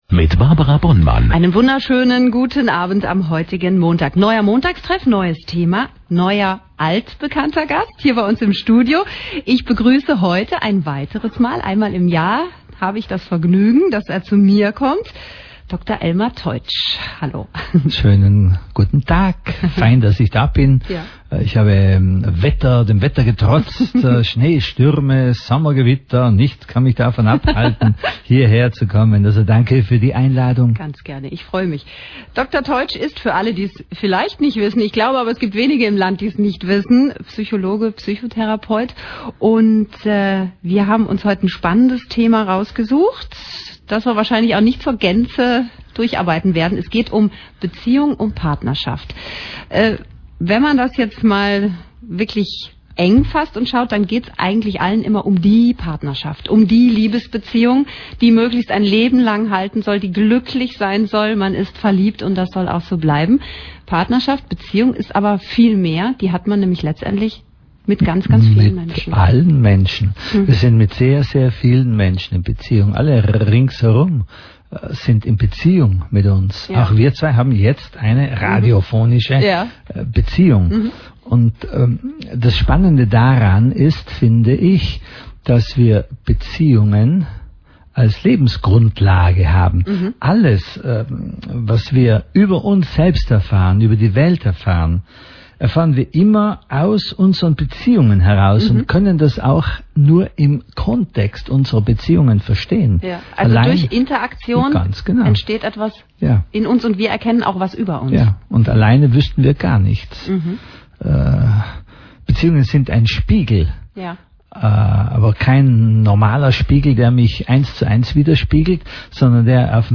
Die Radiosendung